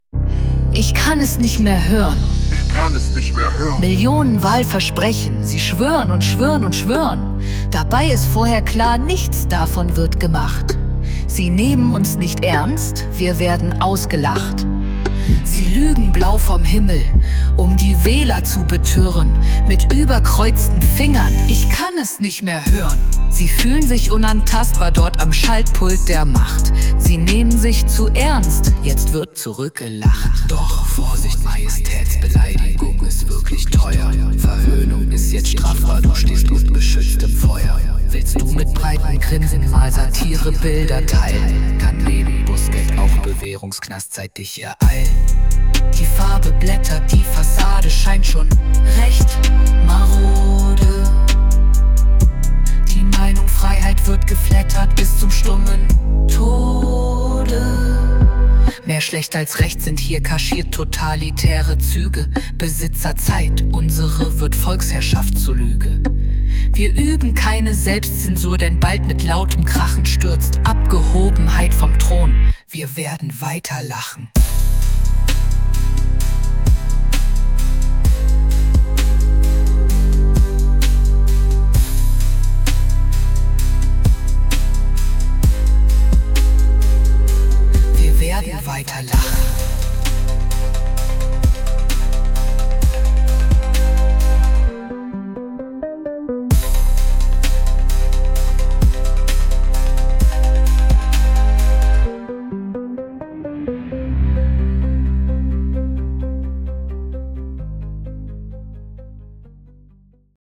KI-gestütztes Audiodesign